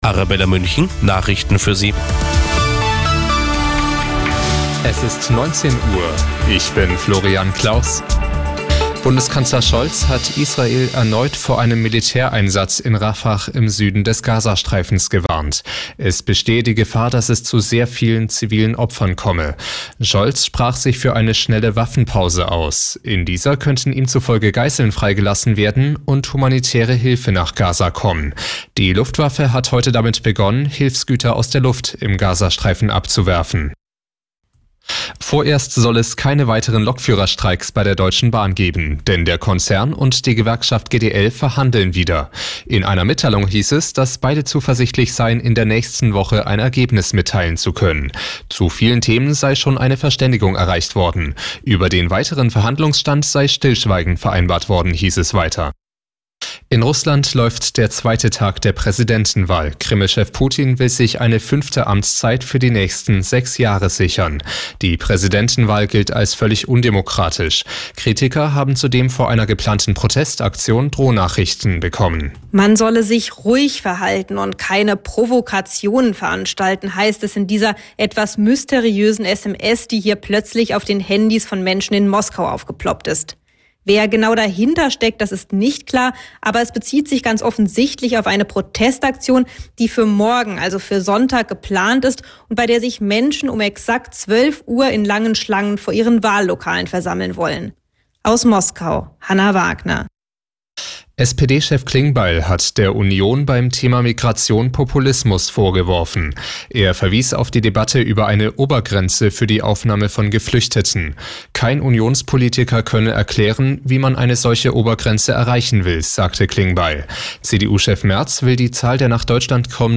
Die aktuellen Nachrichten von Radio Arabella - 17.03.2024